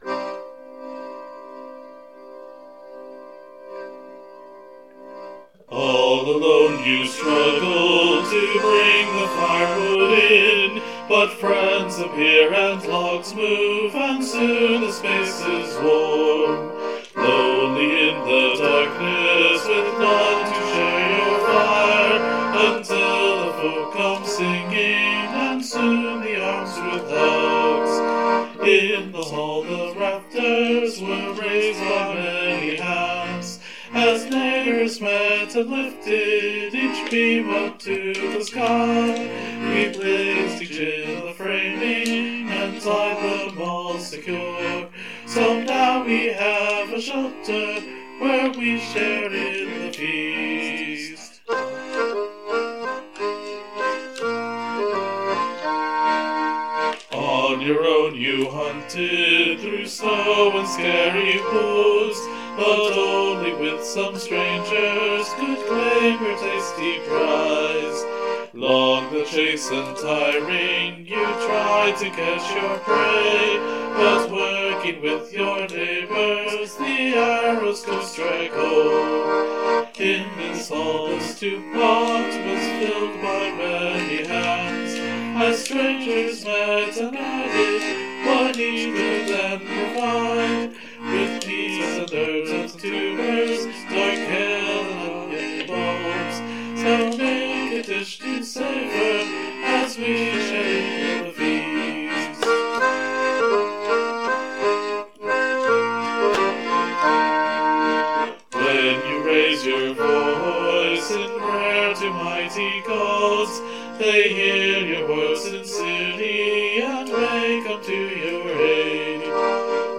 Musically, this should be pretty straightforward: 5 chords, and a straight rhythm.
I chose to record it with a chorus of vocals because it turns out that songs sound better when sung as a group too rather than working alone.